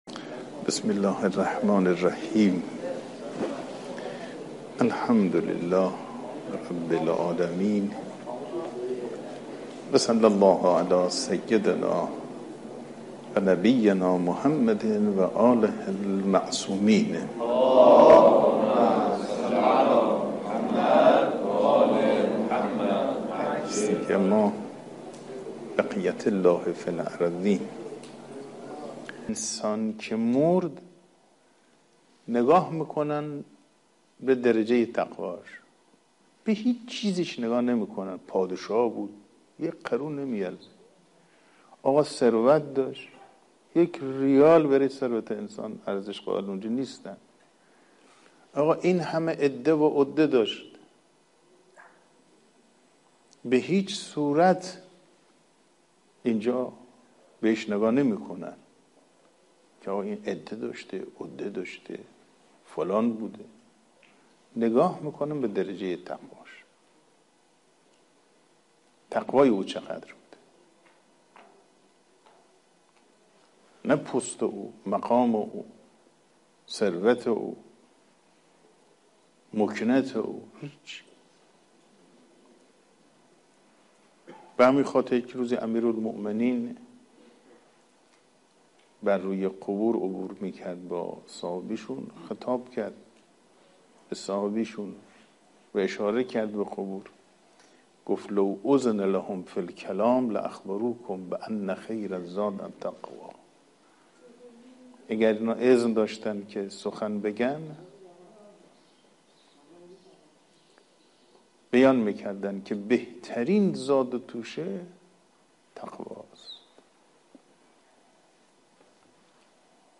صوت| درس اخلاق نماینده ولی فقیه در استان بوشهر
حوزه/ درس اخلاق حجت‌الاسلام والمسلمین صفایی بوشهری نماینده ولی‌فقیه در استان بوشهر در مدرسه علمیه امام خمینی (ره) بوشهر برگزار شد.